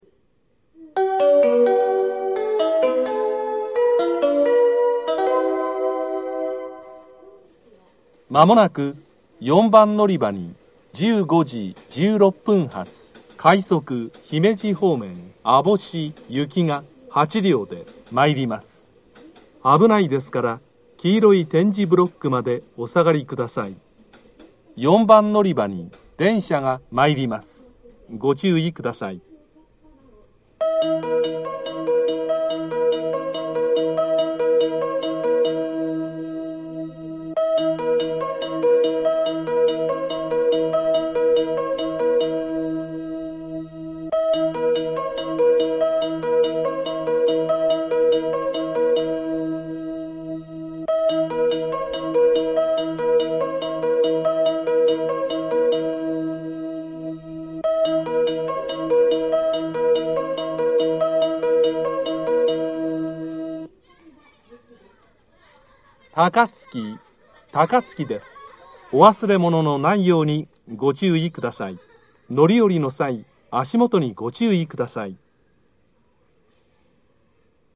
（男性）
接近放送・到着放送
Panasonicクリアホーン　1〜6番線